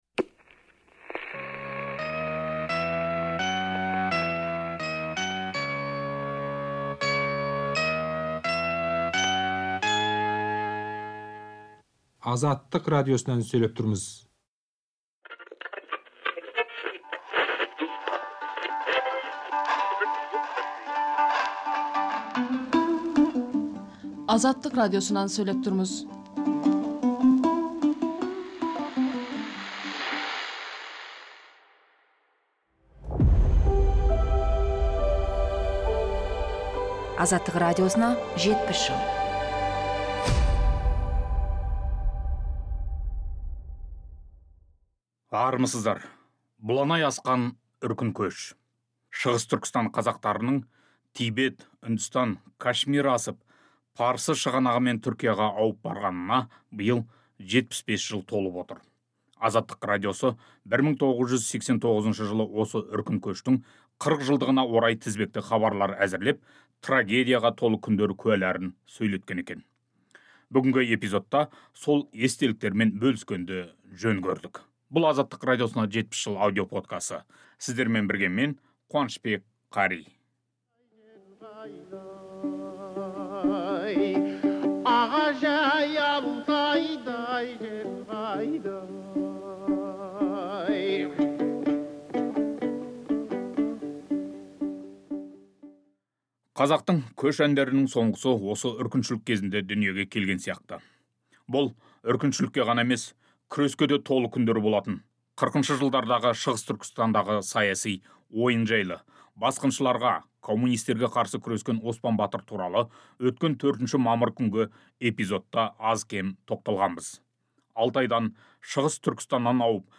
Шығыс Түркістан қазақтарының Такла - Макан, Гоби, Тибет, Үндістан, Кашмир асып, Парсы шығанағымен Түркияға ауып барғанына 75 жылдан асты. Азаттық радиосы 1989 жылы осы үркін кезіндегі Қалибек көшінің 40 жылдығына орай тізбекті хабарлар әзірлеп, трагедияға толы күндер куәларын сөйлеткен.